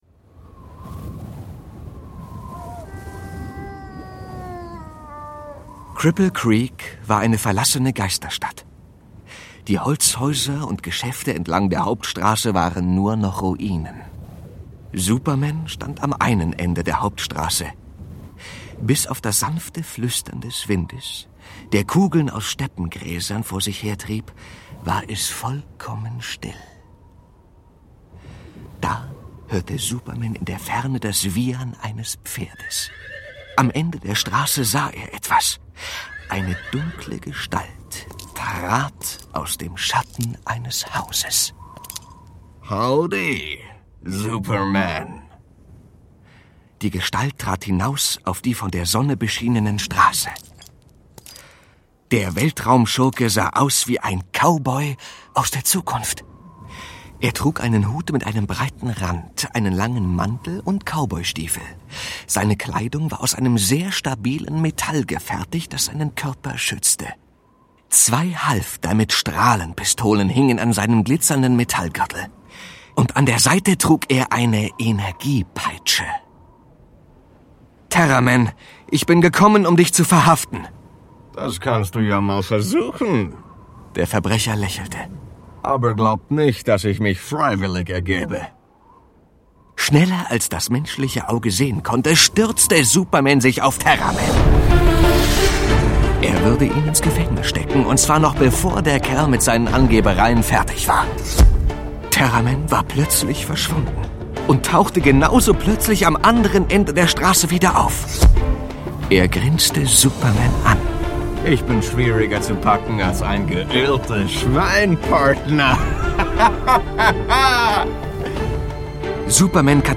Mit vielen Lasereffekten, Raketen und Explosionen ist ein knallbuntes und energiegeladenes Comichörbuch für kleine (und große) Superheldenfans entstanden.